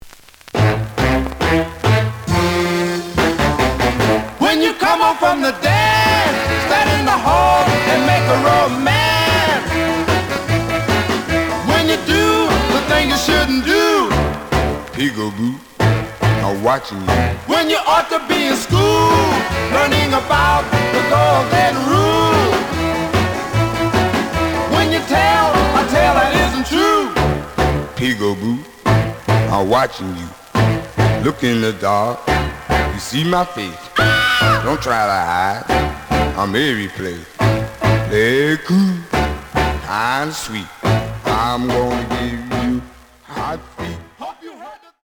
試聴は実際のレコードから録音しています。
●Genre: Rhythm And Blues / Rock 'n' Roll
G+, G → 非常に悪い。ノイズが多い。